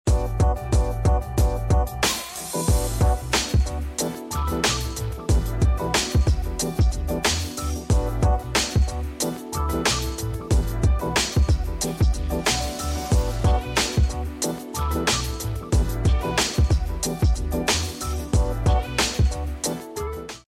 Royalty Free Music